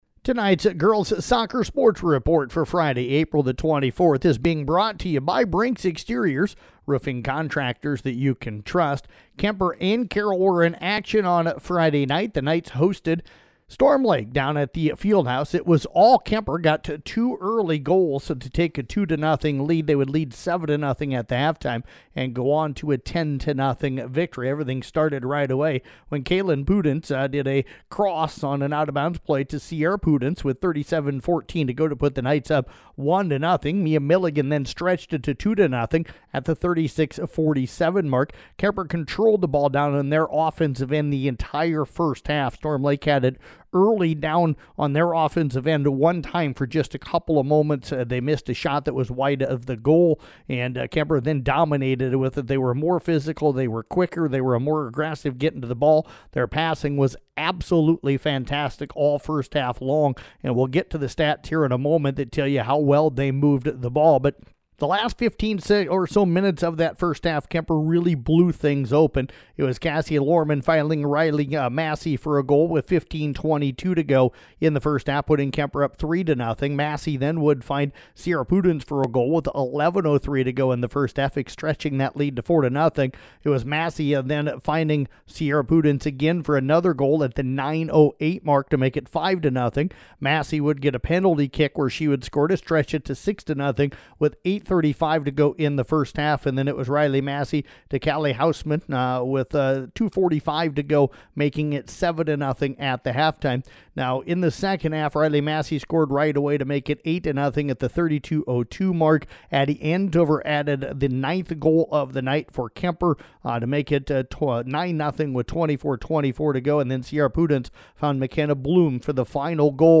Below is an audio recap of Girls Soccer for Friday, April 24th
girls-soccer-recap-friday-april-24th.mp3